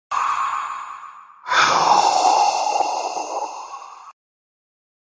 Tiếng Xì khói, Phụt khói… khi tức giận
Tiếng Gầm Xịt khói tức giận hoạt hình… Tiếng Xịt khói, gầm gừ, hét giận dữ…
Thể loại: Hiệu ứng âm thanh
Khi nhân vật giận dữ, có thể nghe thấy tiếng “xìiii”, “phì phì”, “xịt ra hai bên”, kèm hiệu ứng khói trắng phụt từ tai hoặc mũi.
tieng-xi-khoi-phut-khoi-khi-tuc-gian-www_tiengdong_com.mp3